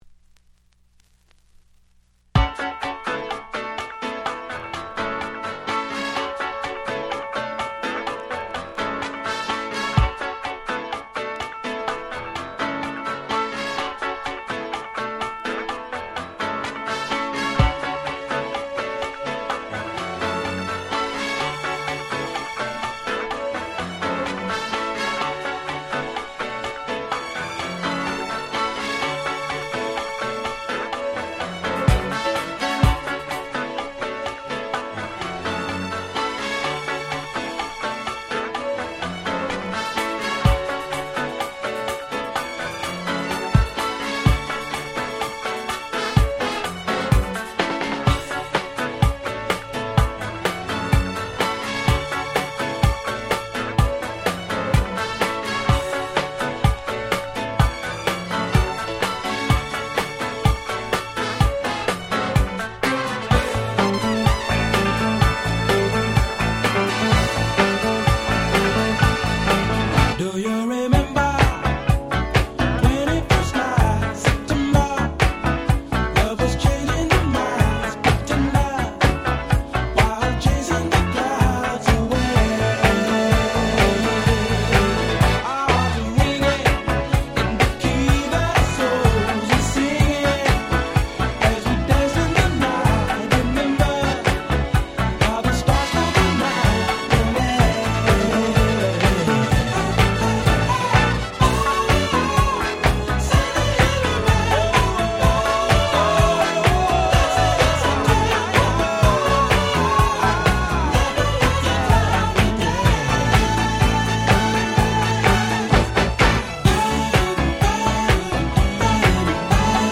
02' Very Nice Dance Classics Remixes !!